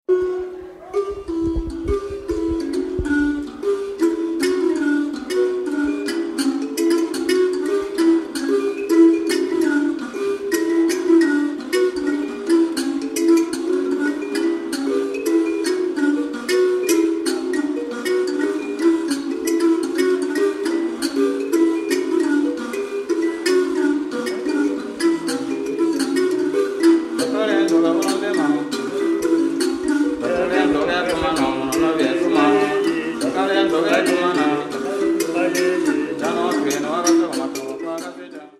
Kankobela players